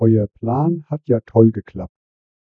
Added Griffin Lim vocoder samples
sample04-griffin-lim.wav